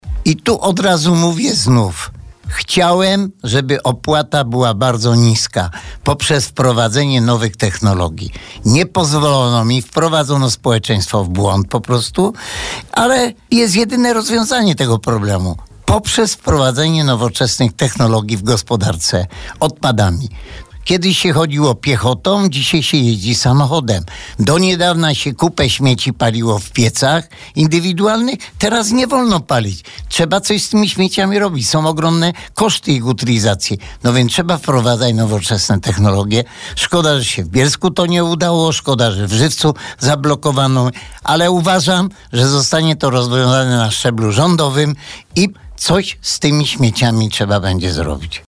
W rozmowie z nami burmistrz Antoni Szlagor podkreśla, że wzrastająca ilość odpadów to problem nie tylko Żywca, ale wszystkich miast w Polsce.